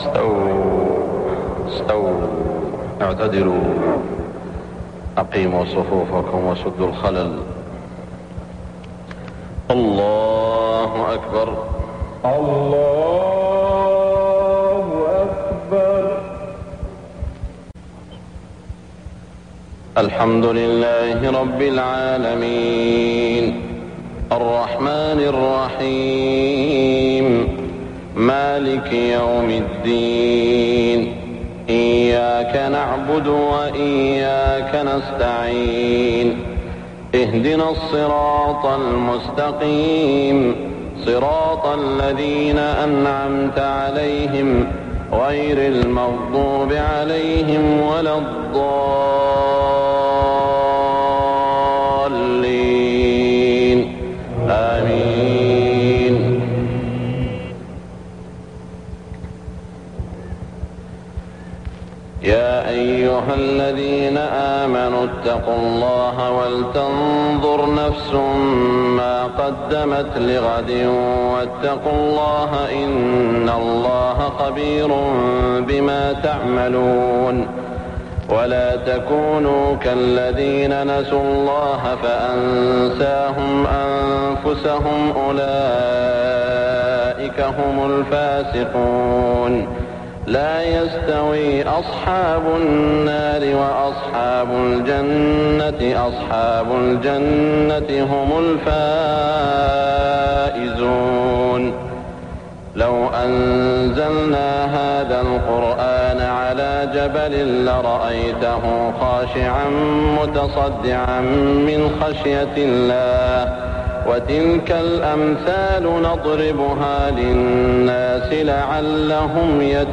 صلاة المغرب 1417هـ خواتيم سورة الحشر > 1417 🕋 > الفروض - تلاوات الحرمين